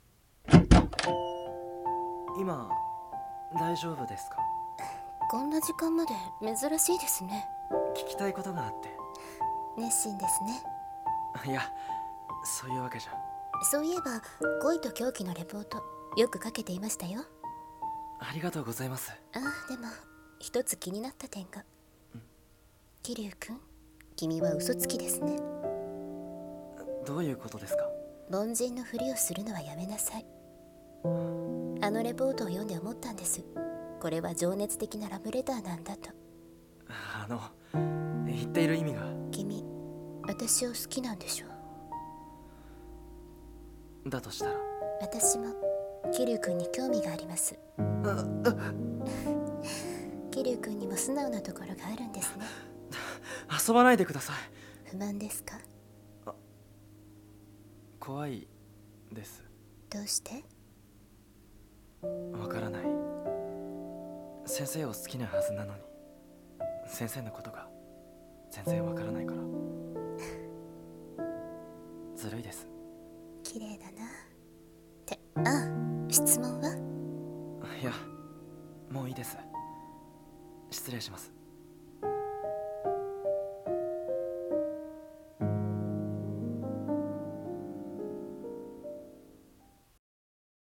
【声劇】恋と狂気